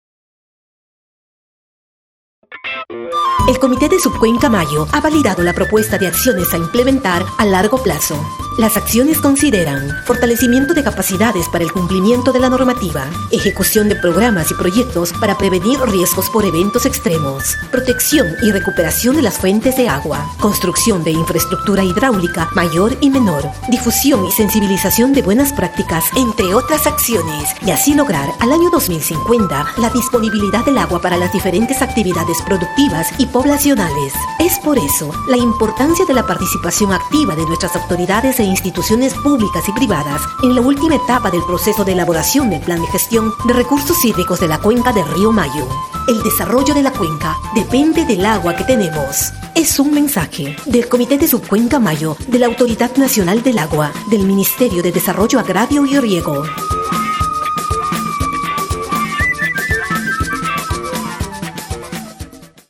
Spot: Validación de las propuestas a implementar en el largo plazo (año 2050) en la cuenca del río Mayo